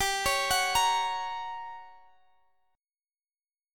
Listen to Gb/G strummed